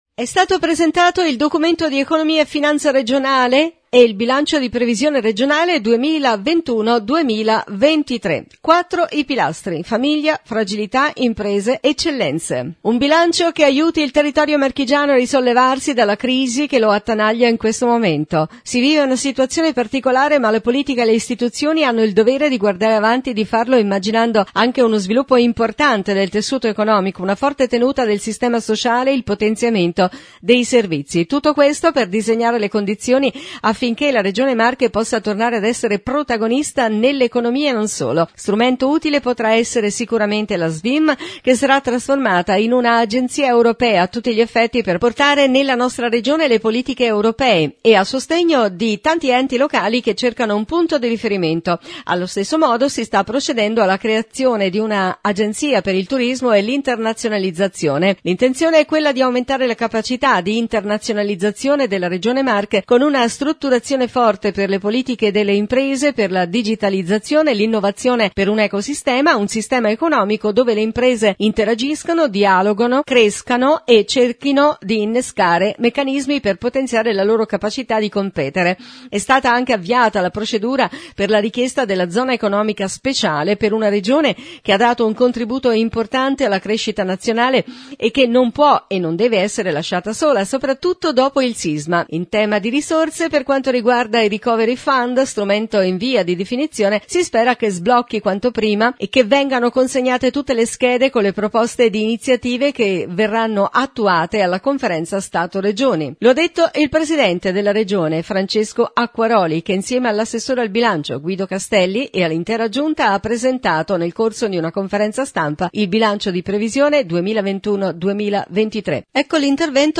Francesco Acquaroli – Presidente Regione Marche Guido Castelli – Assessore regionale al Bilancio